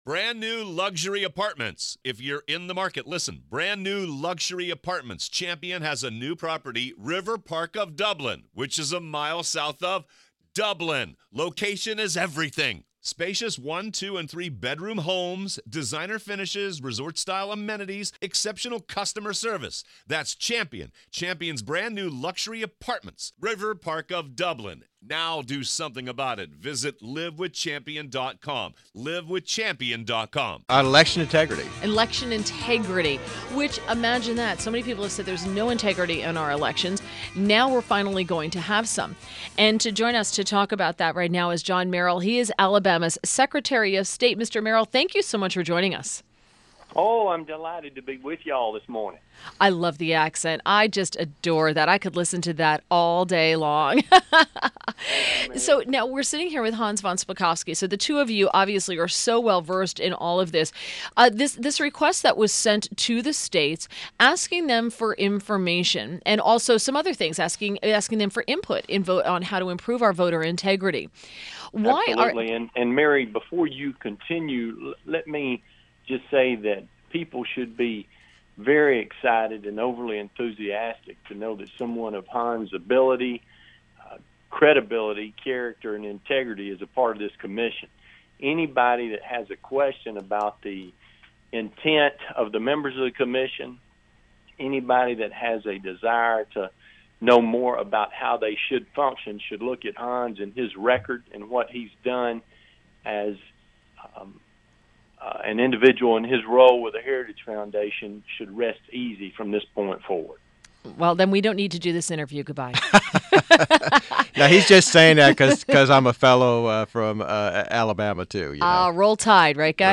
WMAL Interview - JOHN MERRILL 07.07.17
JOHN MERRILL - Alabama's Secretary of State Topic : Trump Voter Data Request • In the past week election officials in dozens of states have rejected a request from the newly-formed Presidential Advisory Commission on Electoral Integrity to provide voter records for a study on the extent (if any) of election fraud.